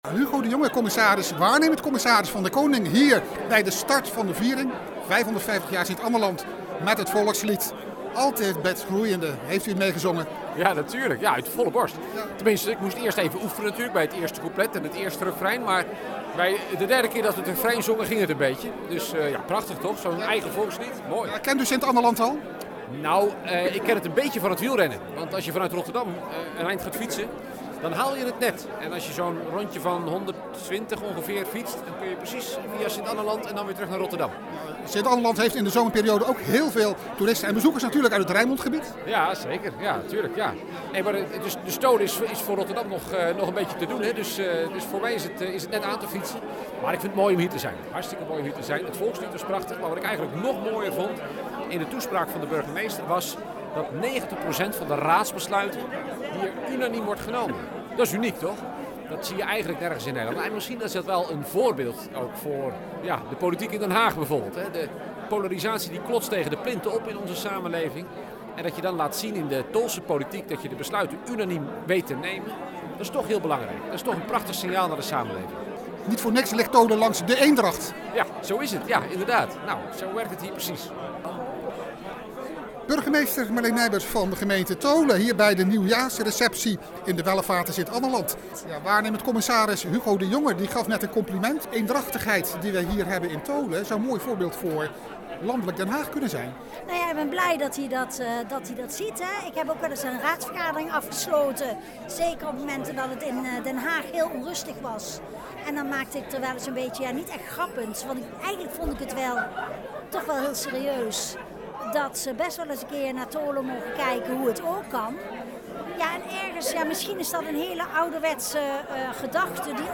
Tijdens de nieuwjaarsreceptie van de gemeente Tholen in Sint-Annaland heeft hij de raad geprezen voor de eendrachtige besluitvorming.